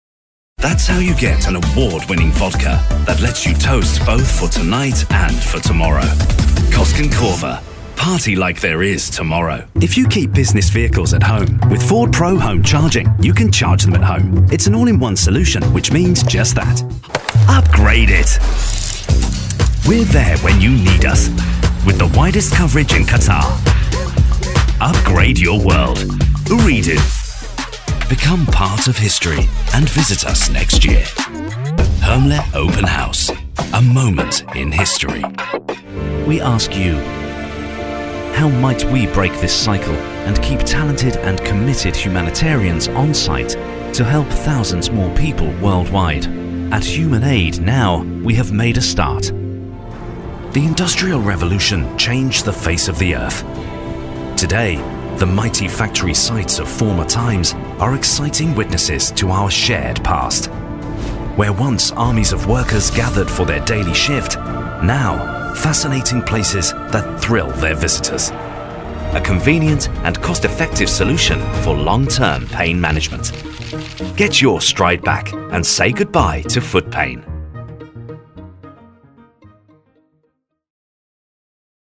Inglés (Reino Unido)
Demo comercial
EV RE-20, interfaz UAD
BarítonoBajoProfundoBajo
CálidoConversacionalCreíbleVersátilDiversiónAmistosoCon carácter